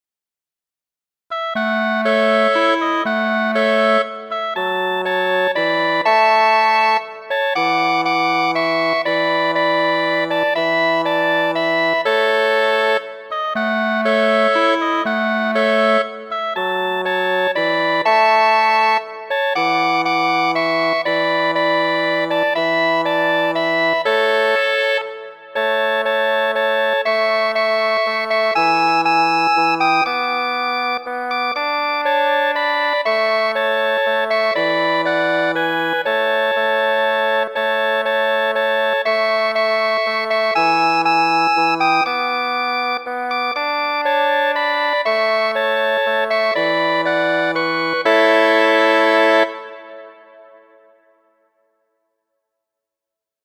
Verkante, infana popolkanto, en aparta versio de mi mem.